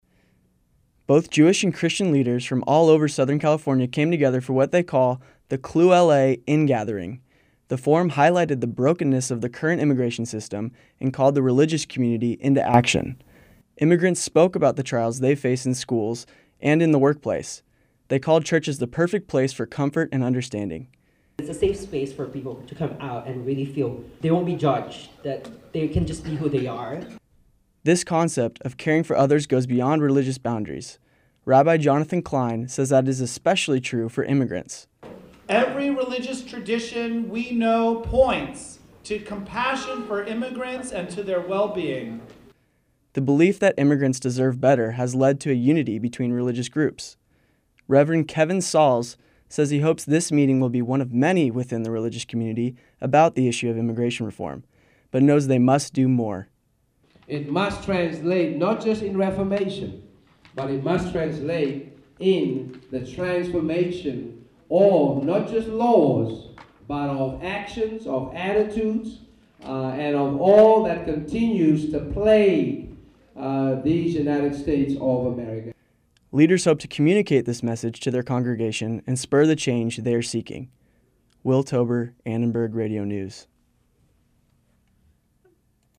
Both Jewish and Christian teachers came together at Holman United Methodist Church in south Los Angeles for what they call the "Clue LA In gathering." The forum highlighted the brokenness of the current immigration system and called the religious community into action.